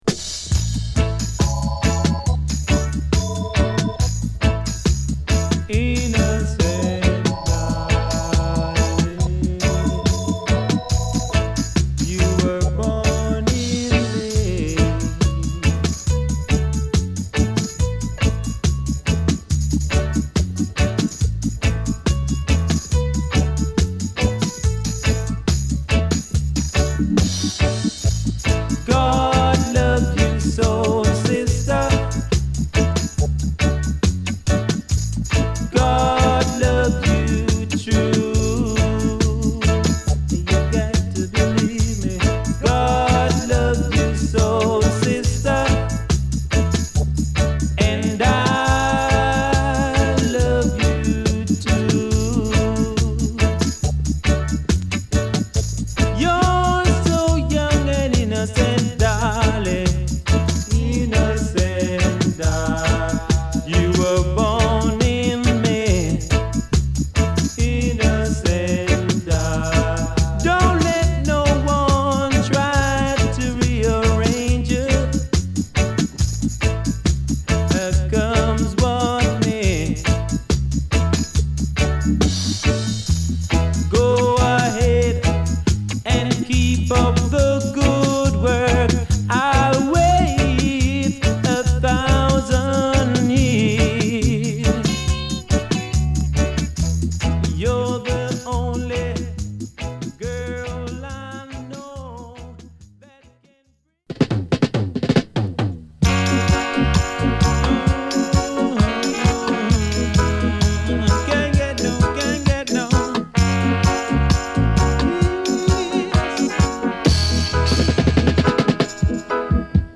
ジャマイカ、キングストン出身。